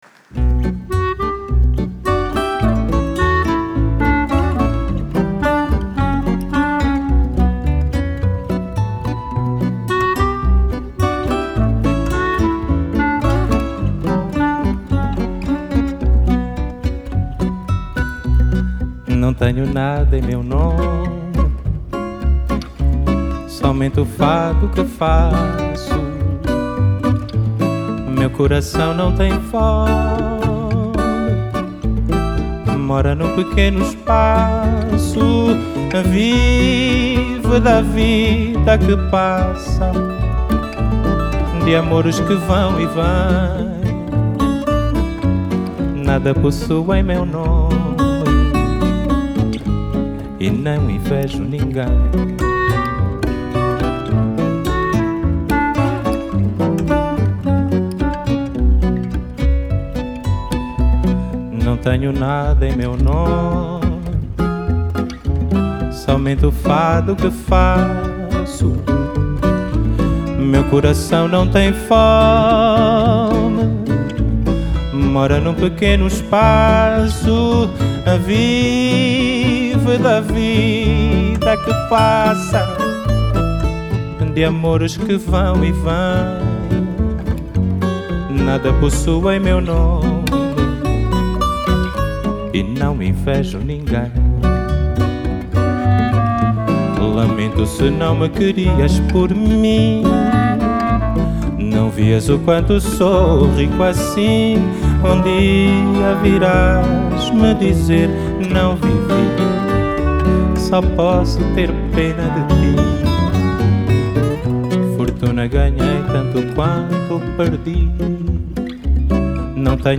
Genre: Fado, Fado Bossa Nova, Folk, World